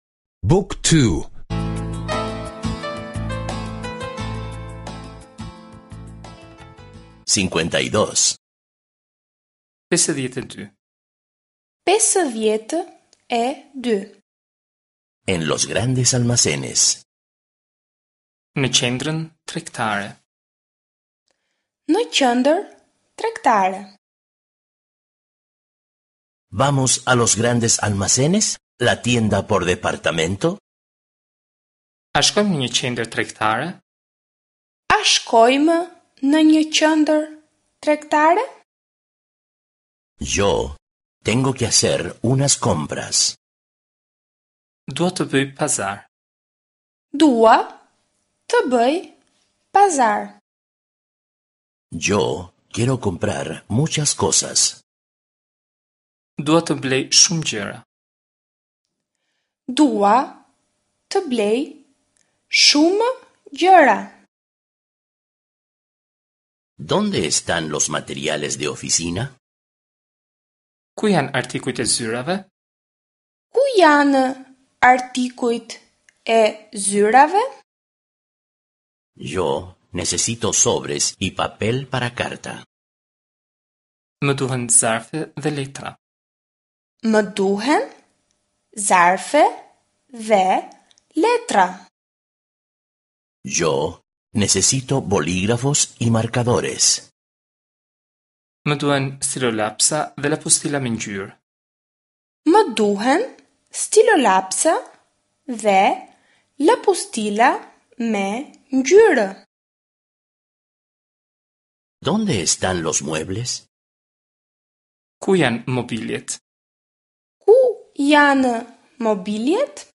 Curso de audio de albanés (escuchar en línea)